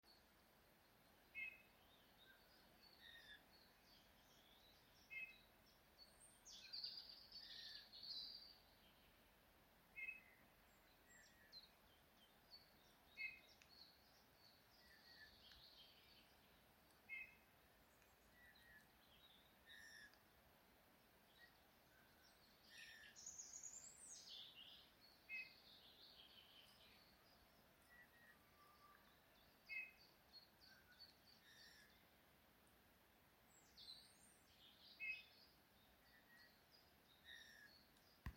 Bullfinch, Pyrrhula pyrrhula
Administratīvā teritorijaLīvānu novads
StatusSinging male in breeding season